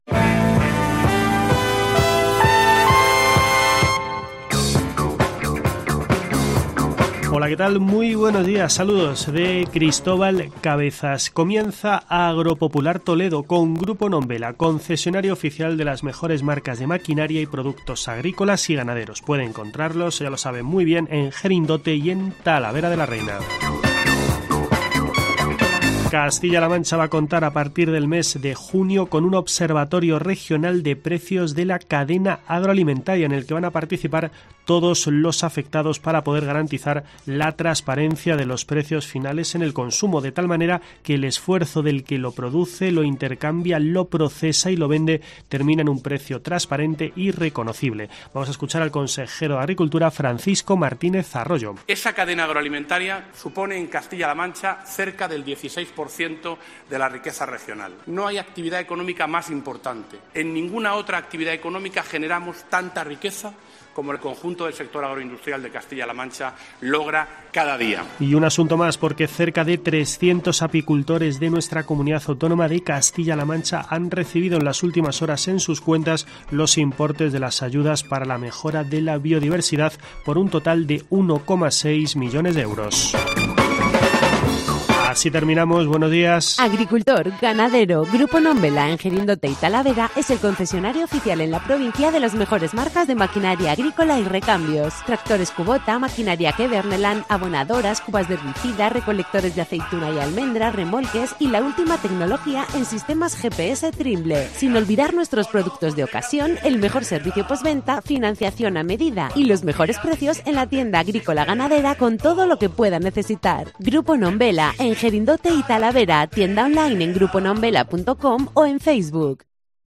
Escucha en la parte superior de esta noticia el boletín informativo de COPE Toledo dedicado al mundo de la agricultura y la ganadería.